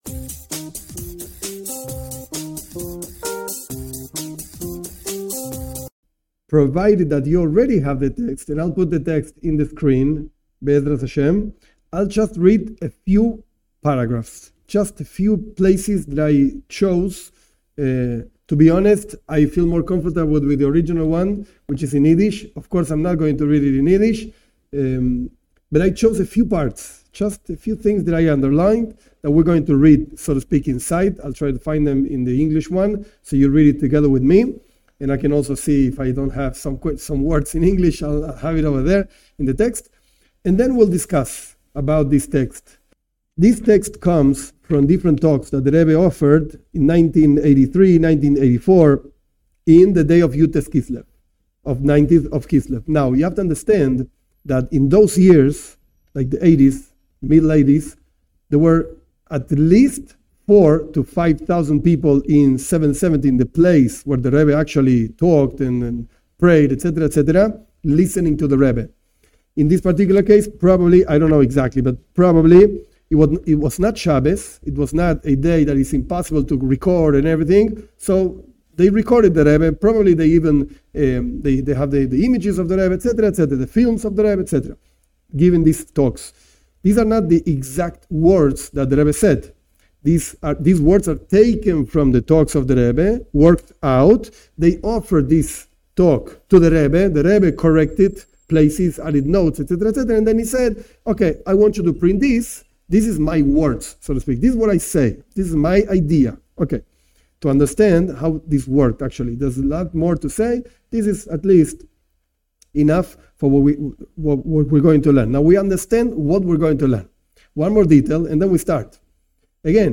This class is a review of the text of a Talk by The Rebbe on the 19th of Kislev (1983-1984) where the Rebbe explains a letter written by the founder of Chabad, Rabbi Shneur Zalman of Liadi, regarding his liberation from the imprisonment in Zarist Russia in 1789.